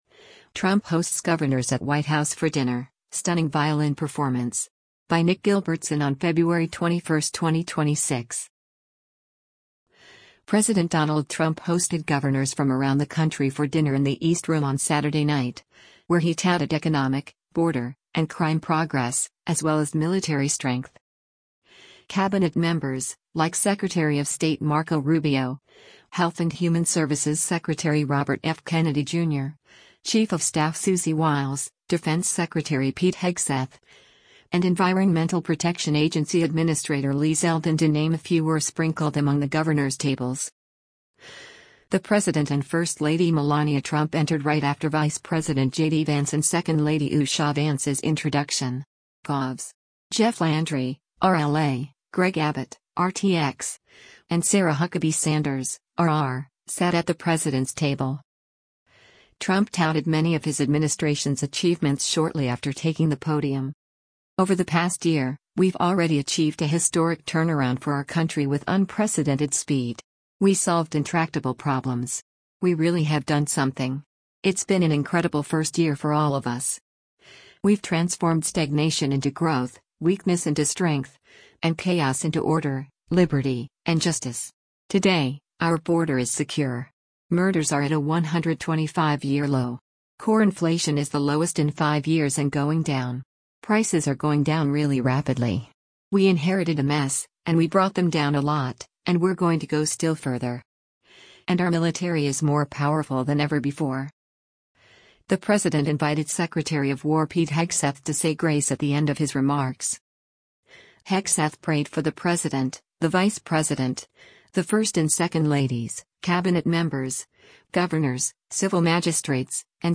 Trump Hosts Governors at White House for Dinner, Stunning Violin Performance
violinist